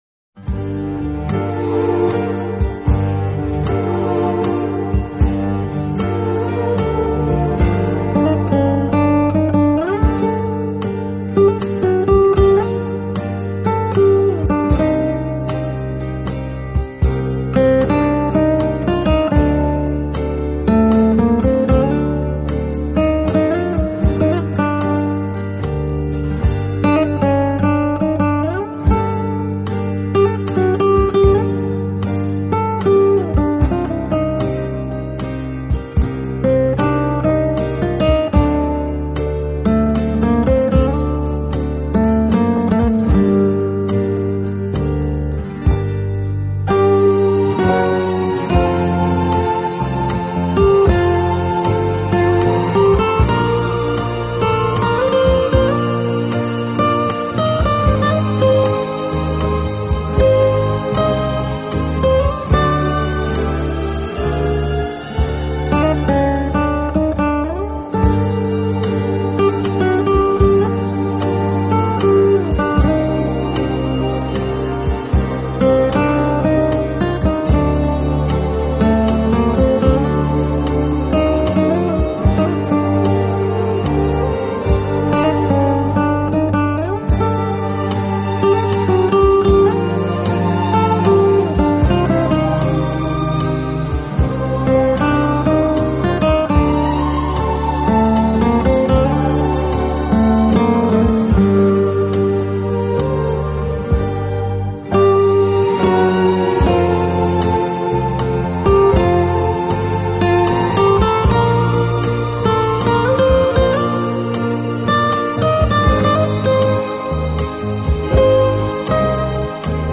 【轻音吉他】
音乐风格：Instrumental, Acoustic Guitar